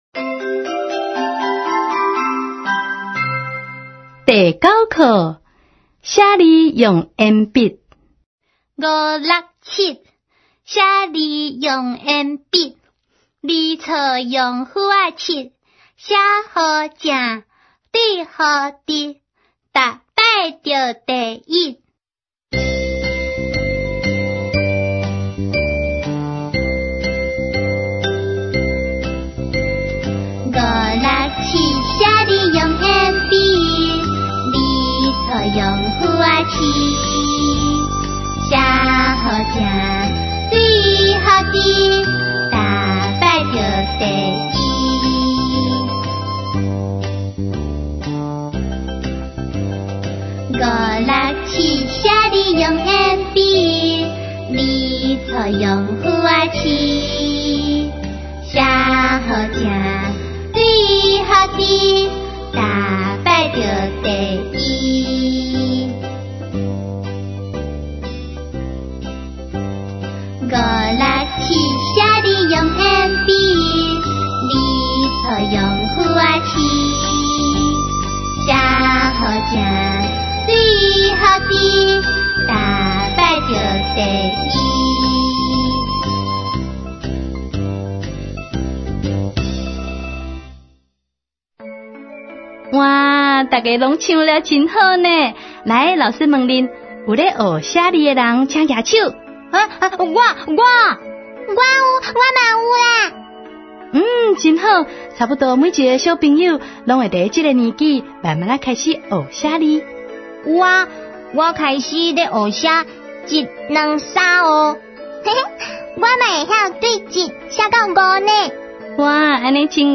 ● 兒歌唱遊、常用語詞 ●